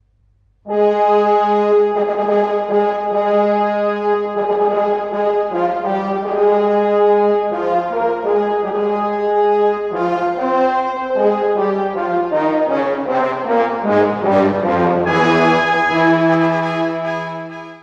↑古い録音のため聴きづらいかもしれません！（以下同様）
冒頭から、「運命」のモチーフが威嚇する響きで放たれます。
運命のモチーフの主張が終わると、沈痛な面持ちの第一主題が現れます。